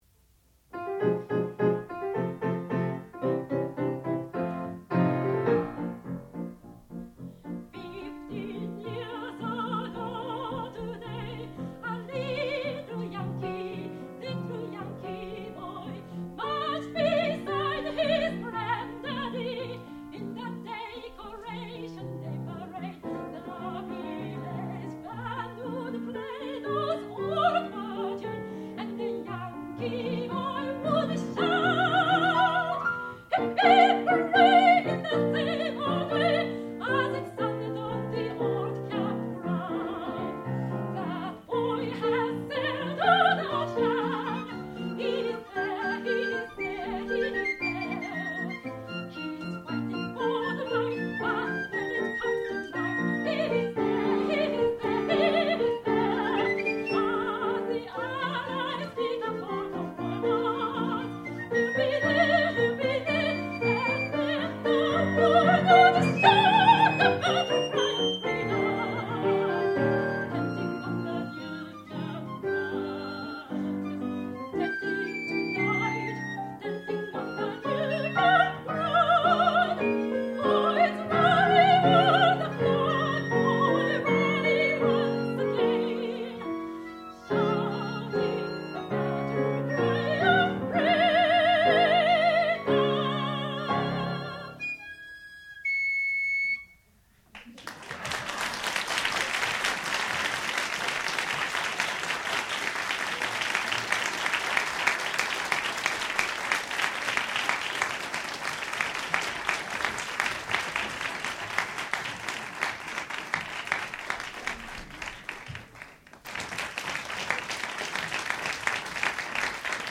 sound recording-musical
classical music
piccolo
piano
soprano
Master's Recital